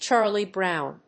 音節Chàrlie Brówn